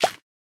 Minecraft Version Minecraft Version snapshot Latest Release | Latest Snapshot snapshot / assets / minecraft / sounds / block / composter / empty1.ogg Compare With Compare With Latest Release | Latest Snapshot